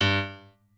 piano4_45.ogg